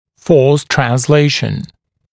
[fɔːs trænz’leɪʃ(ə)n] [trɑːn-][фо:с трэнз’лэйш(э)н] [тра:н-]передача силы, усилия